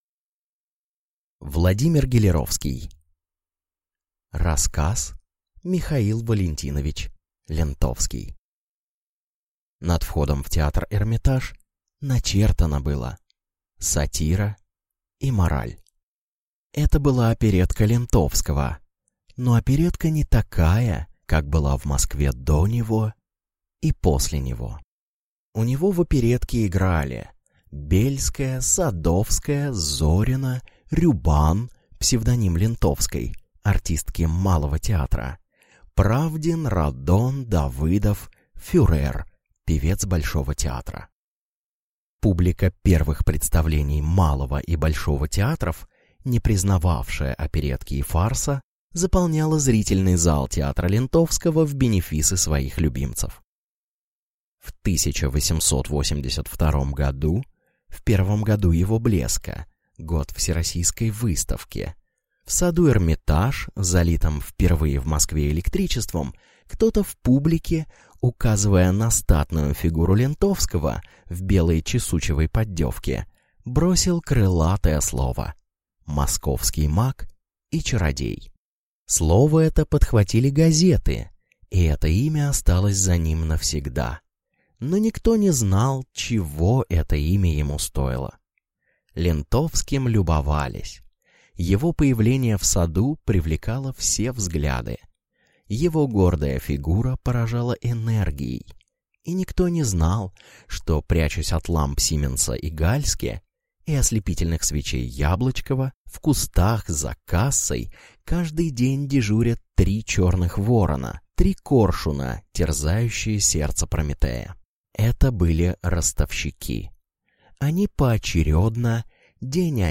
Аудиокнига М. В. Лентовский | Библиотека аудиокниг